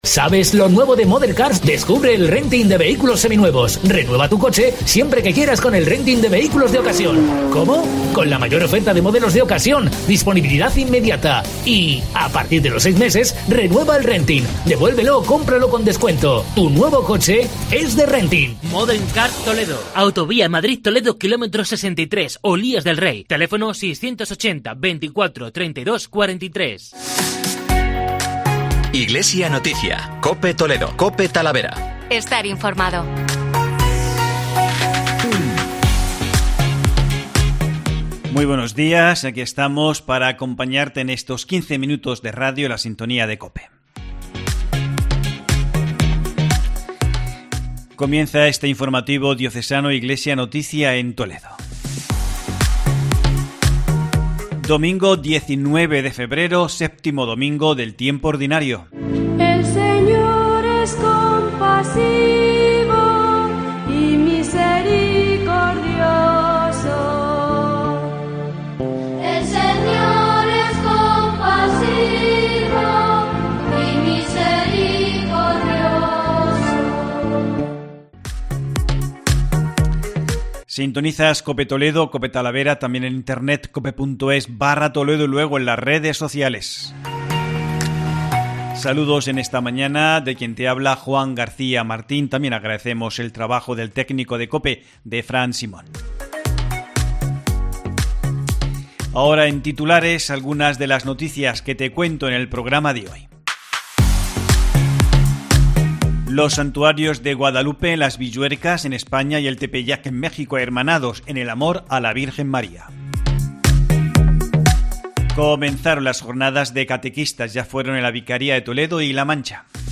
En este informativo Diocesano, repasamos las principales noticias de la Archidiócesis de Toledo